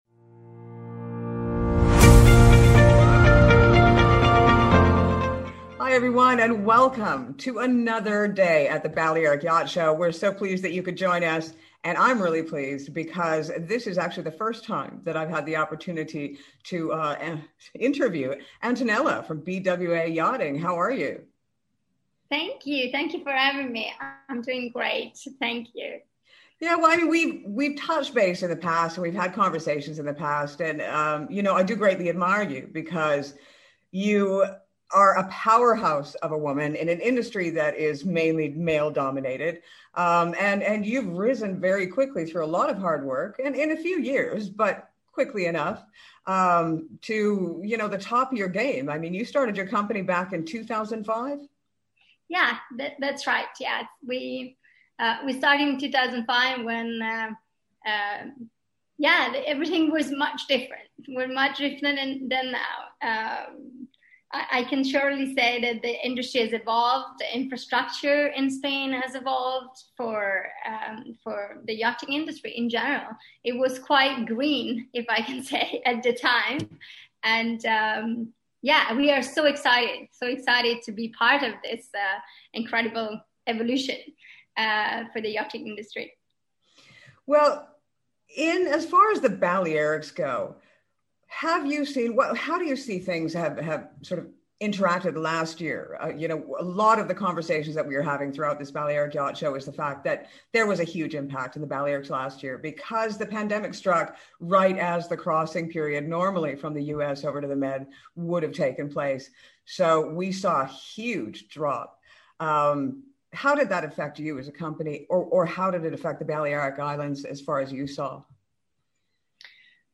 If you missed some of the great interviews that were had during The Balearic Yacht Show, not to worry, we will be bringing you a few of them here!